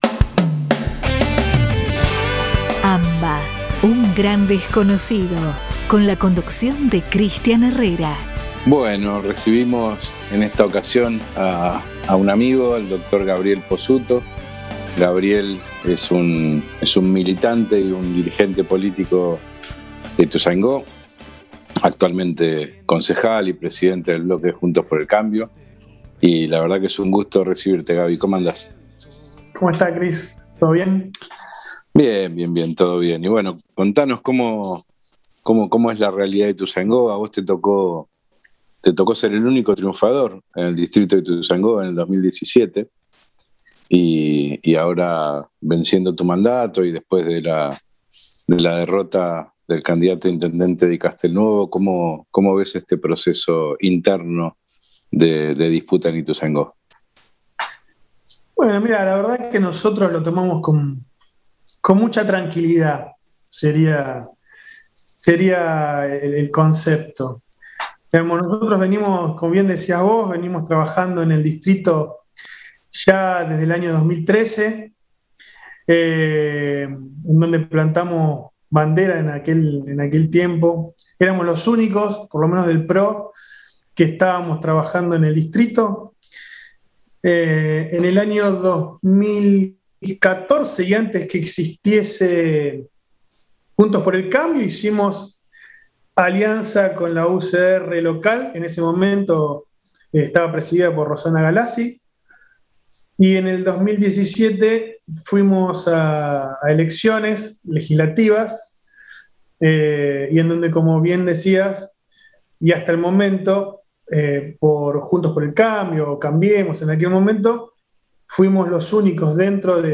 Entrevista a Gabriel Pozzuto Concejal y Presidente del Bloque Juntos por el Cambio Ituzaingó.